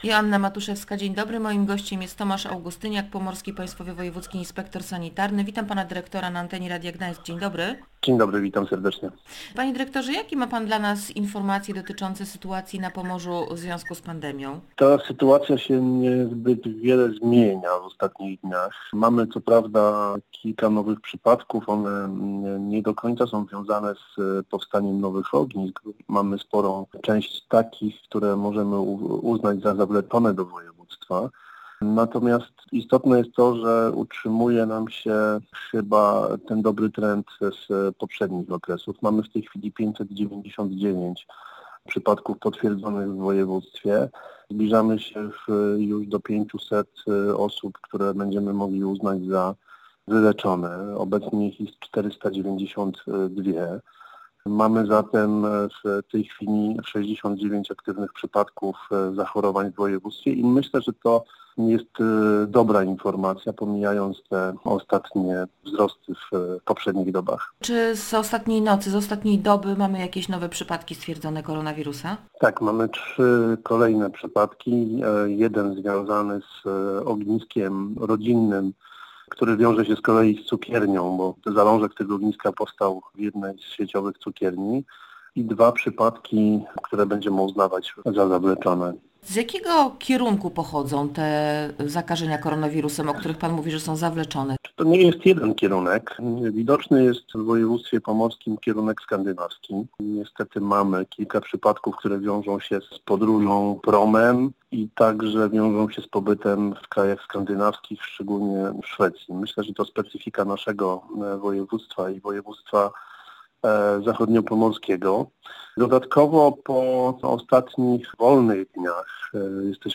Kolejne przypadki stwierdzania koronawirusa na Pomorzu nie oznaczają, że sytuacja w regionie wymknęła się spod kontroli – mówił w Radiu Gdańsk Tomasz Augustyniak, Pomorski Państwowy Wojewódzki Inspektor Sanitarny w Gdańsku. Jego zdaniem mimo stwierdzania nowych przypadków zakażeń, liczba tych aktywnych spada, co stanowi dobry trend.